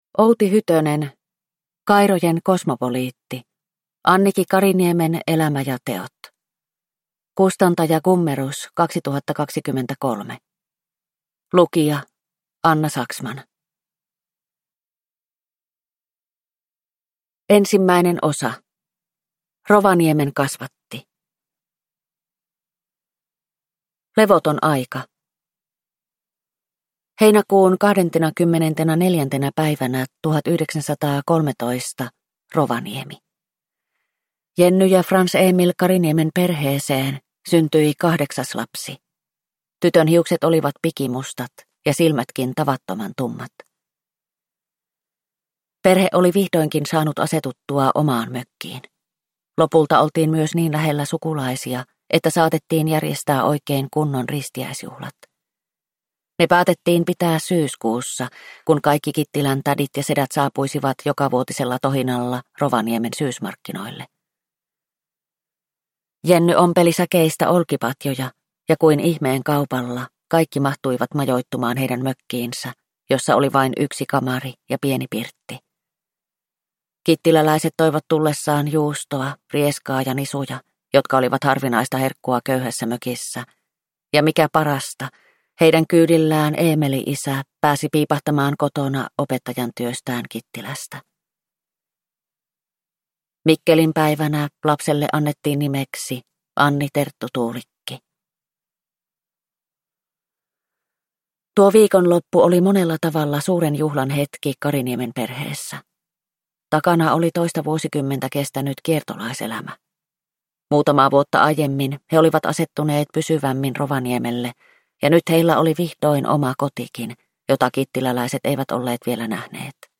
Kairojen kosmopoliitti – Ljudbok – Laddas ner